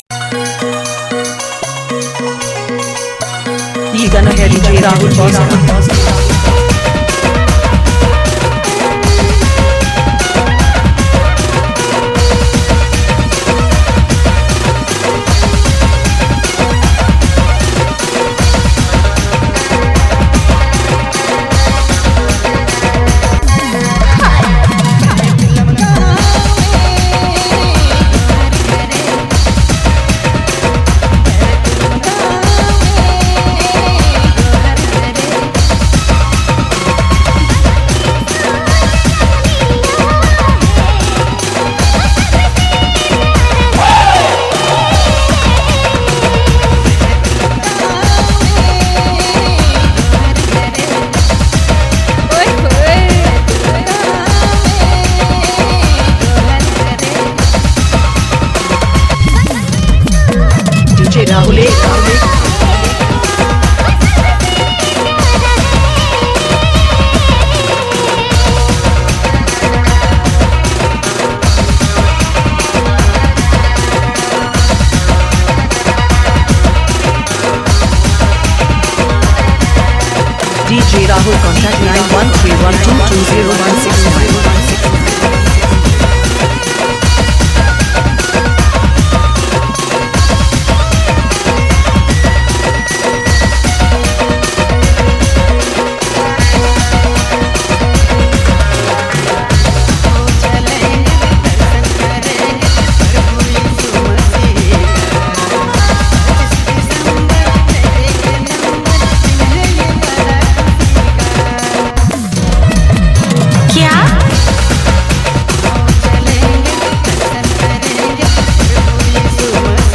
Dj Remixer
Christmas Dhanka Mix Song